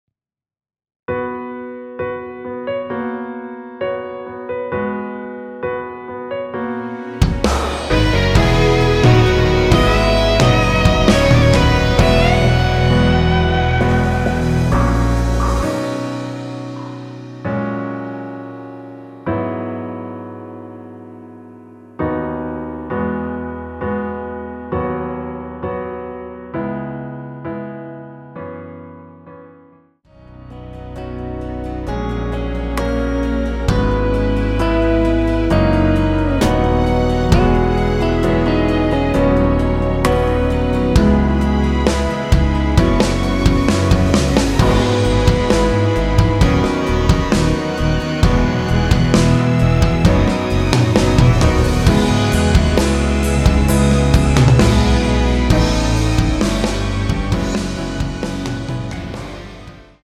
원키에서(-2)내린 (1절앞+후렴)으로 진행되는 MR입니다.
앞부분30초, 뒷부분30초씩 편집해서 올려 드리고 있습니다.
중간에 음이 끈어지고 다시 나오는 이유는